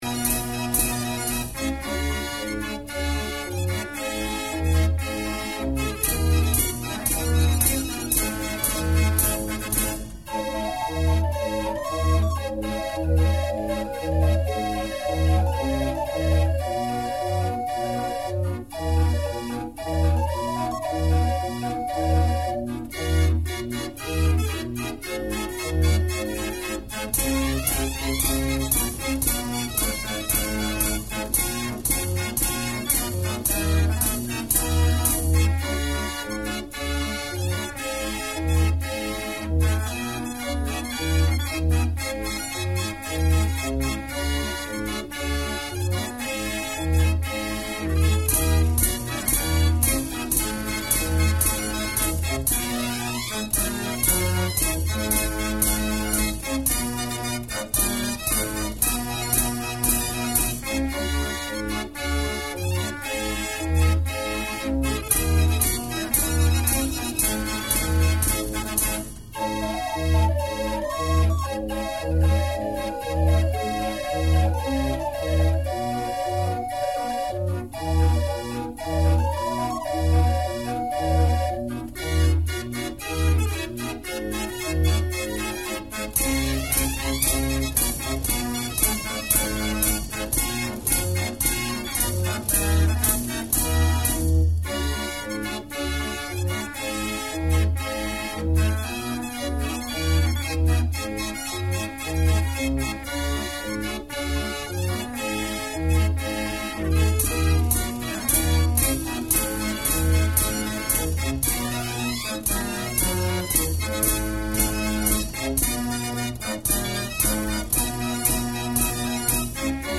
draai-orgel.mp3